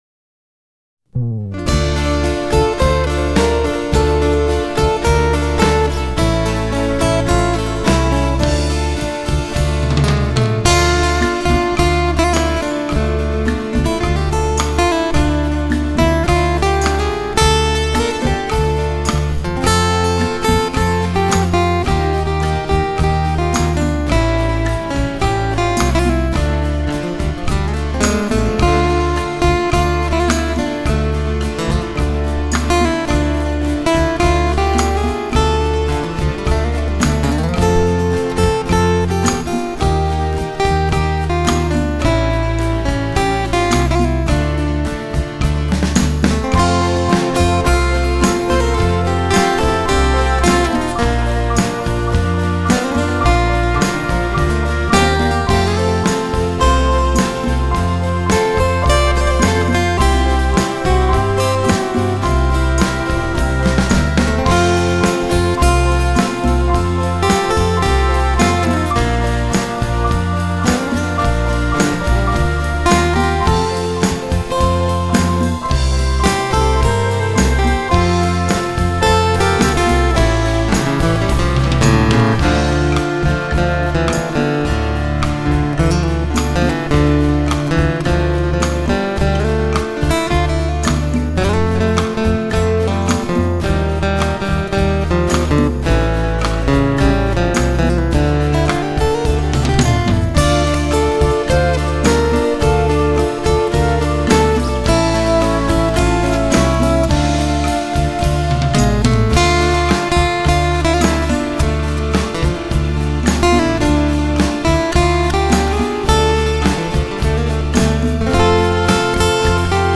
инструментального СЁРФ-РОКА
серф гитариста